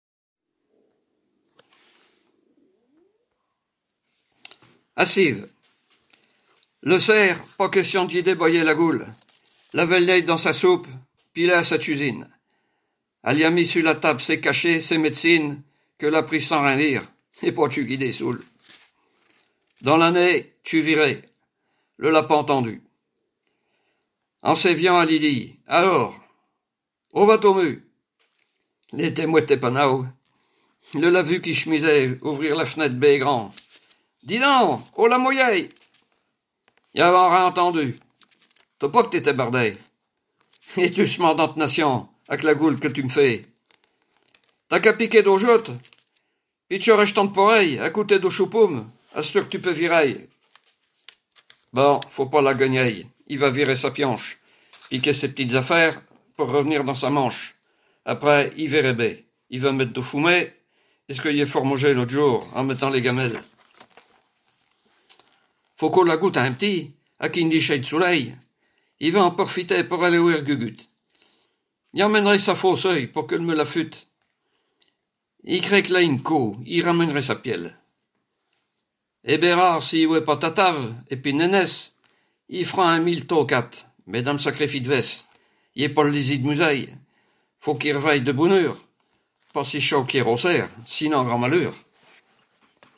Patois local
Poésies en patois